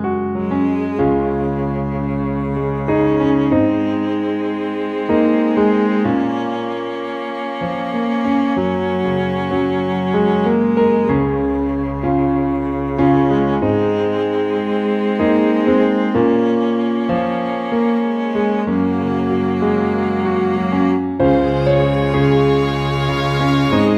Up 3 Semitones For Female